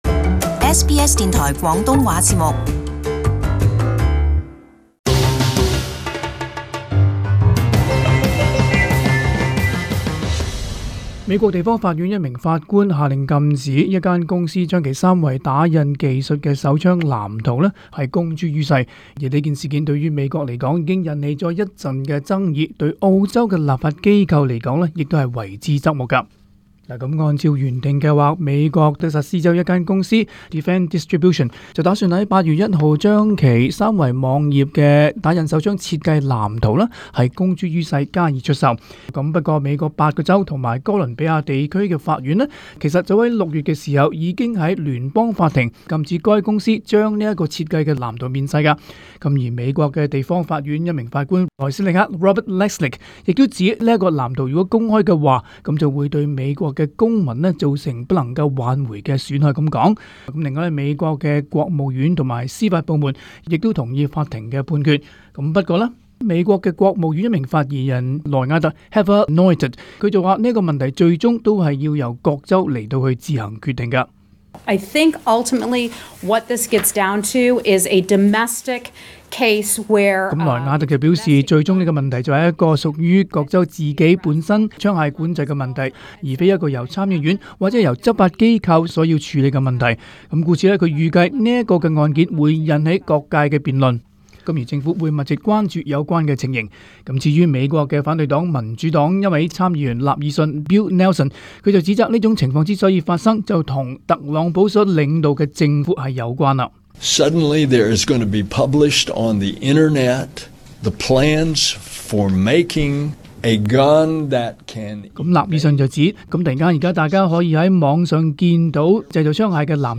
【时事报导】美国法官禁止出售三维打印手枪